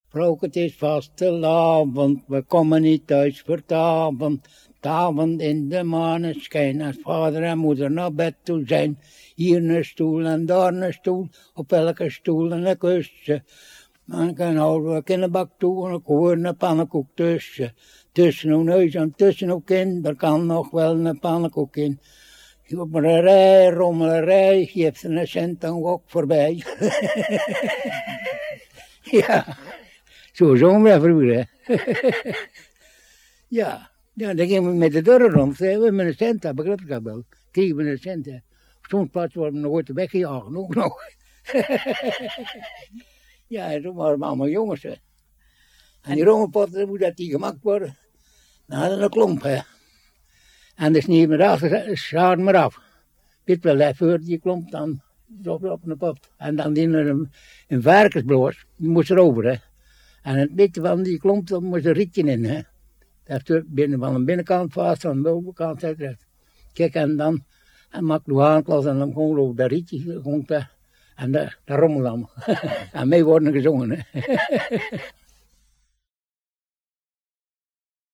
Vastenavondliedje
veldopname